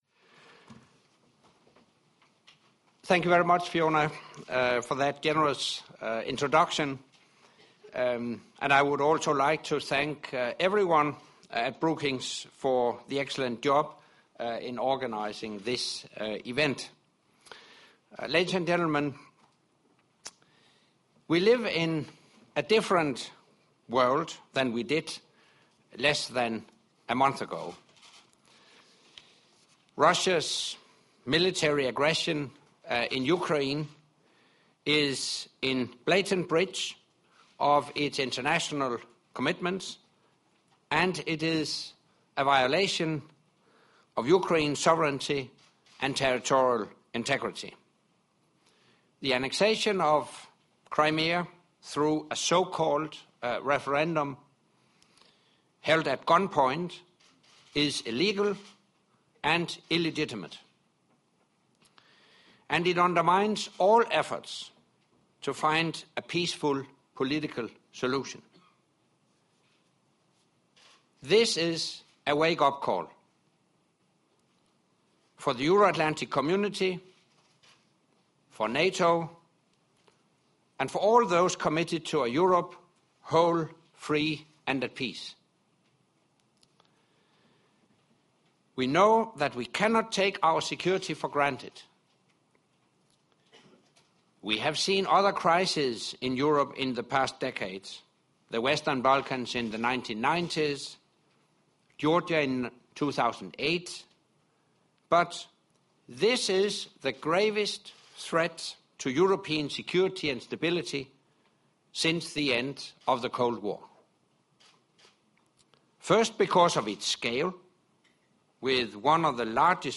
Why NATO Matters to America - Speech by NATO Secretary General Anders Fogh Rasmussen at the Brookings Institution